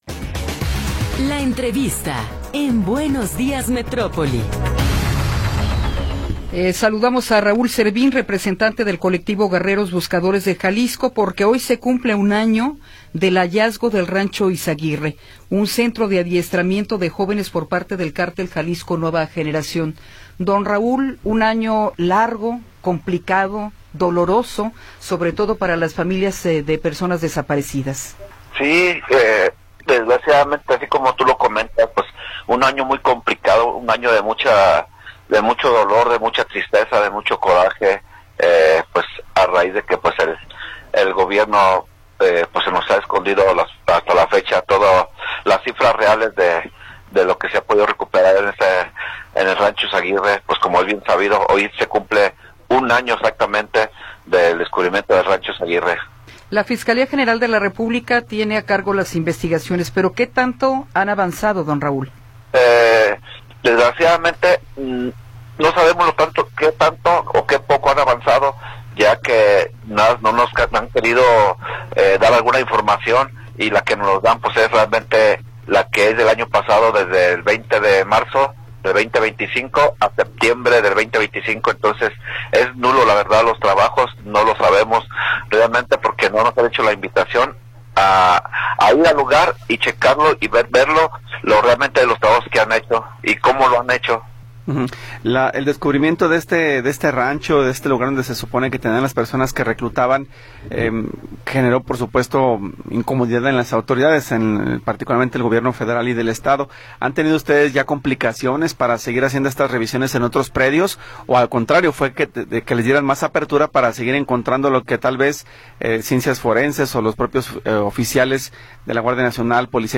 Entrevista
Entrevistas